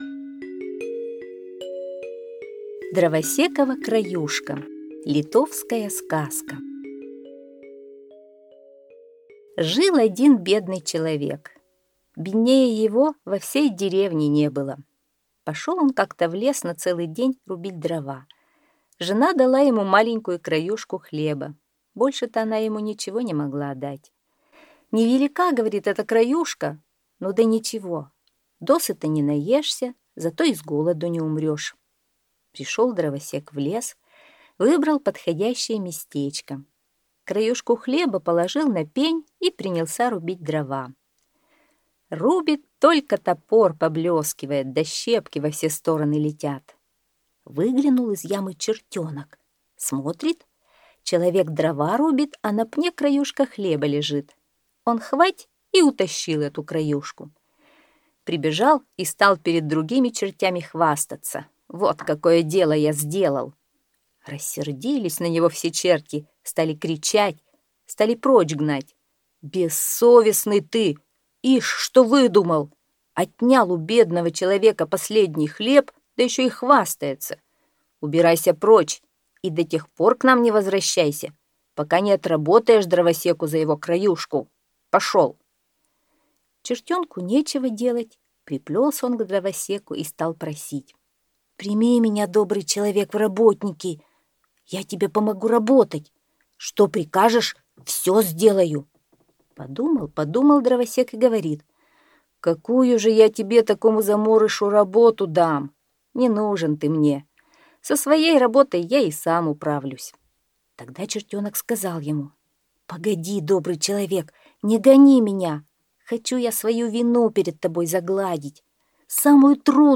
Литовская аудиосказка